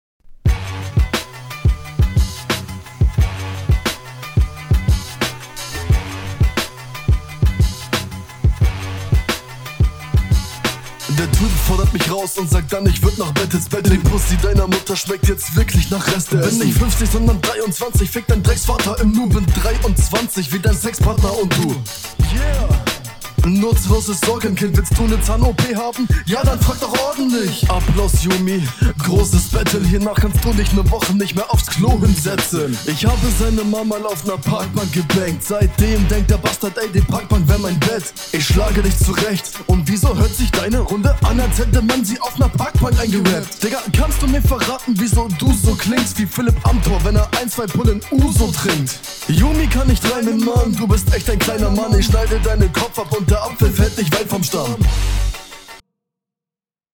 Flow: Ab und an klingt es was abgehackt.